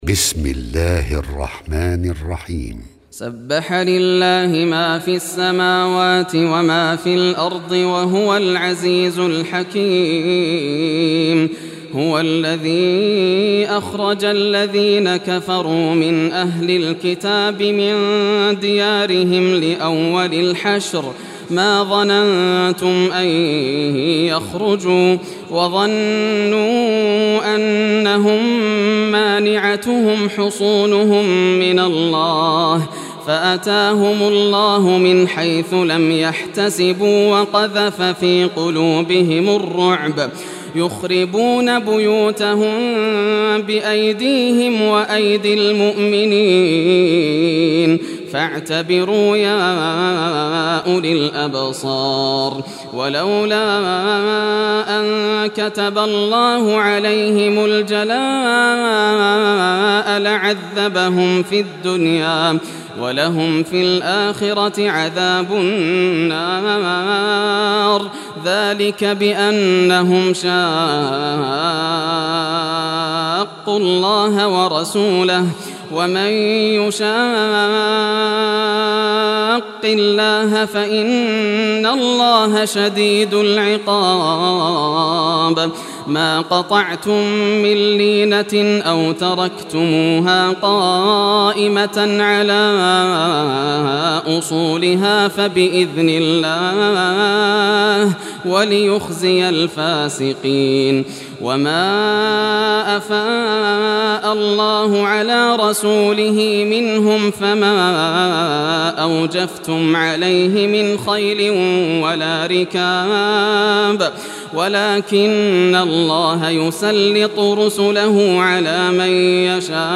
Surah Al-Hashr Recitation by Yasser al Dosari
Surah Al-Hashr, listen or play online mp3 tilawat / recitation in Arabic in the beautiful vocie of Sheikh Yasser al dosari.